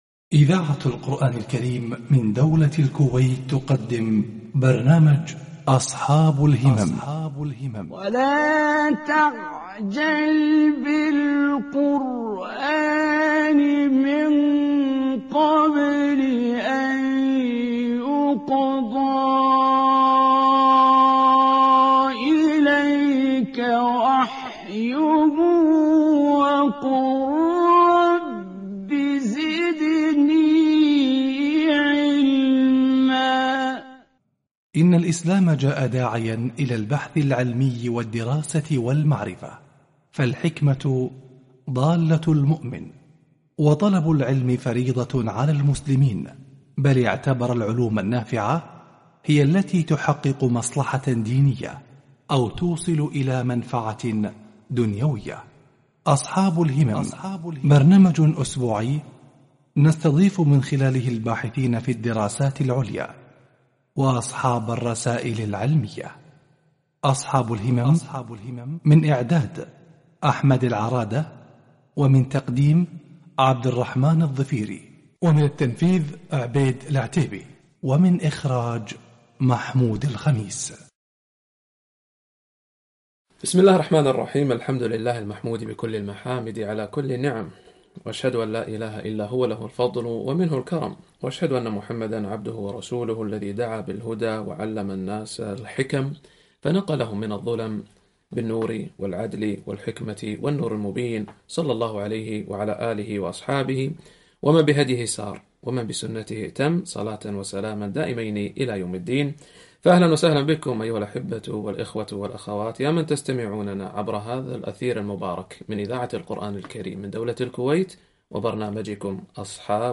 لقاء البرنامج الإذاعي أصحاب الهمم